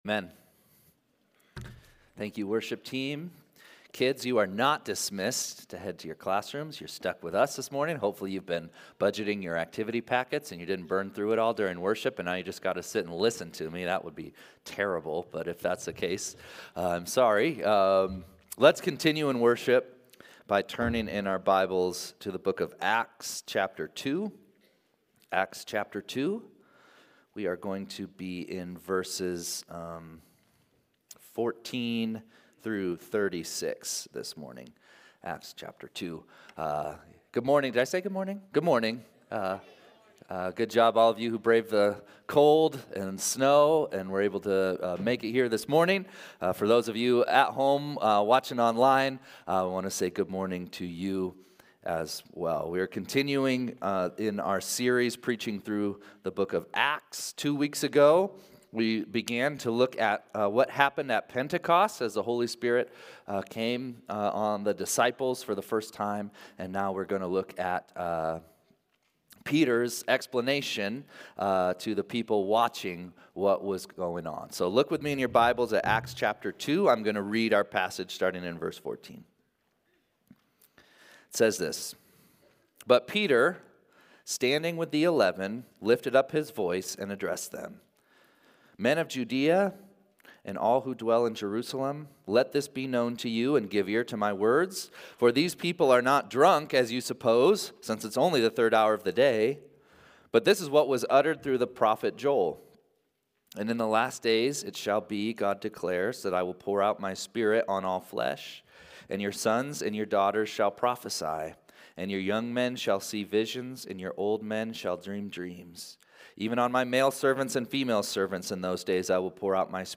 2-16-25-Sunday-Service.mp3